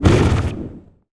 fireball_blast_projectile_spell_05.wav